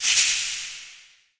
treepop.ogg